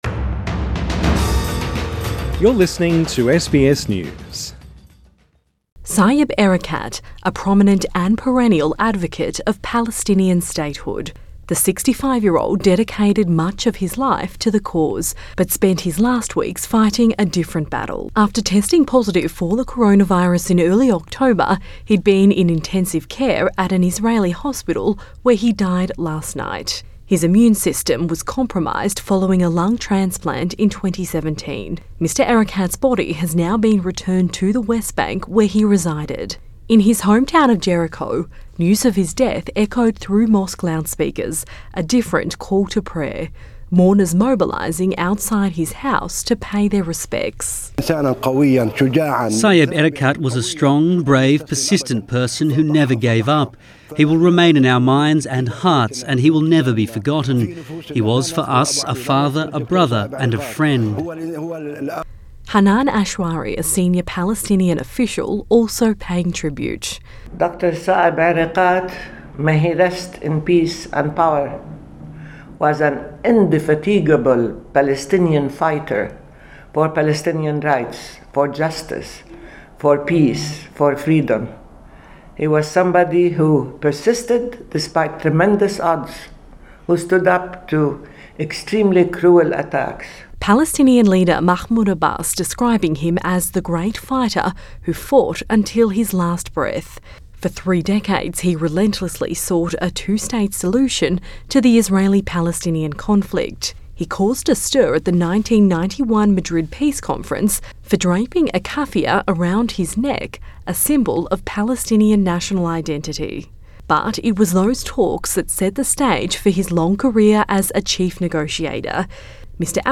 Palestinians are mourning the death of Saeb Erekat [[SY-eb er-uh-KAHT]], a passionate champion who dedicated his life to the Palestinian cause. He died in an Israeli hospital where he was being treated for COVID-19.